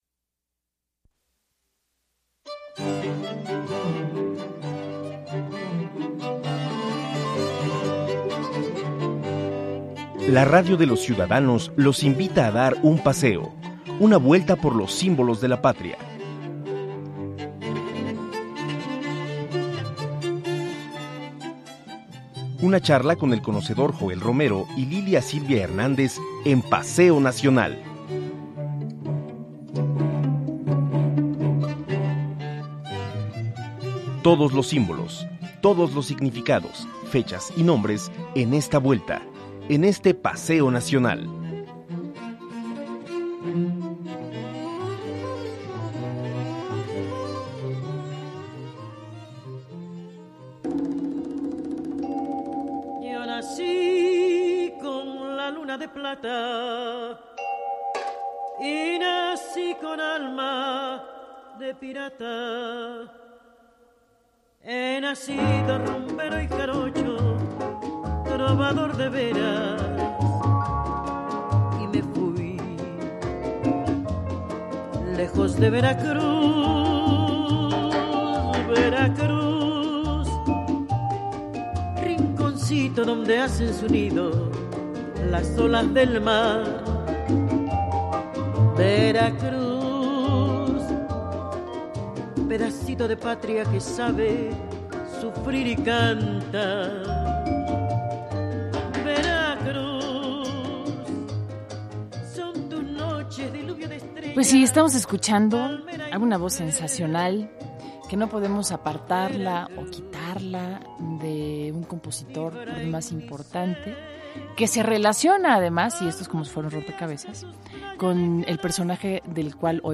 quienes presentan una semblanza de Reyes Heroles, ideólogo veracruzano, en el programa “Paseo Nacional”, transmitido el 29 de abril de 2004